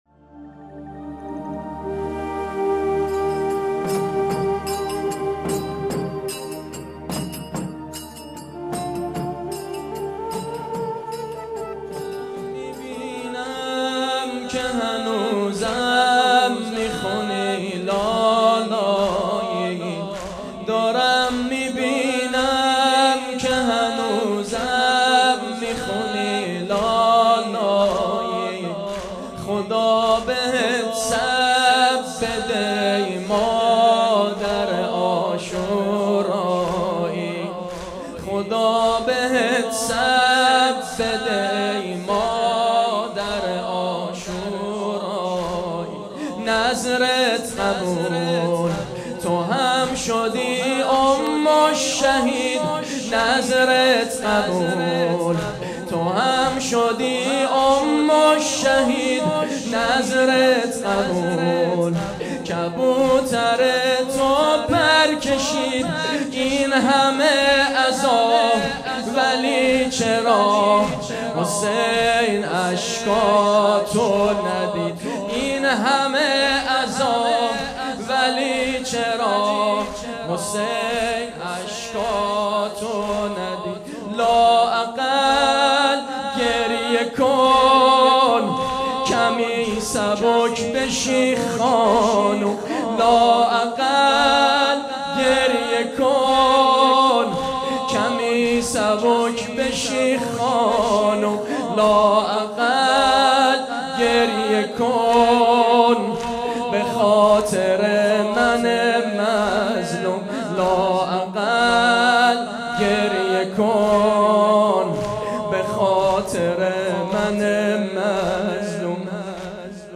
دهه‌ی اول محرم الحرام سال 1399 | هیأت روضة سلام الله علیها الزهرا-دزفول